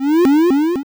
bfxr_Charge.wav